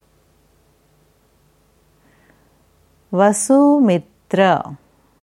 Kurze Vorträge und Erläuterungen zu allen wichtigen Sanskrit Ausdrücken zu Yoga, Ayurveda, Meditation und Ayurveda.